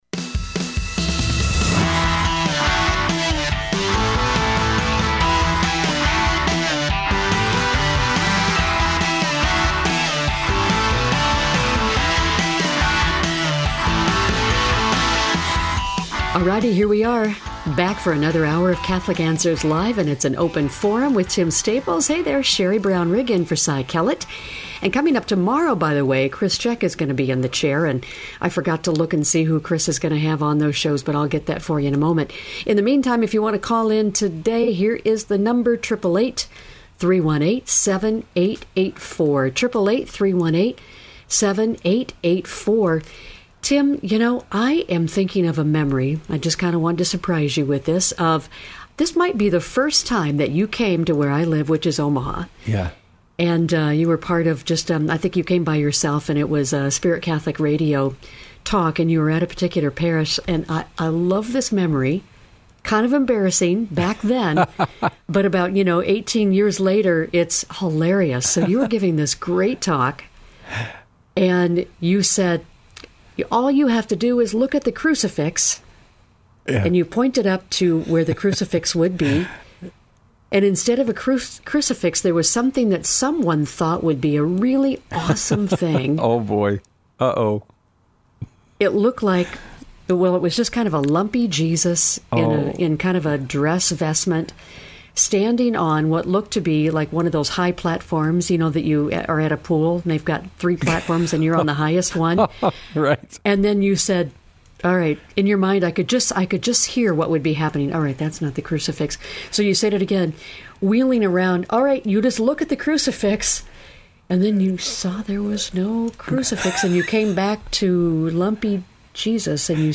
Why do Catholics pray to Mary and the saints? Callers choose the topics during Open Forum, peppering our guests with questions.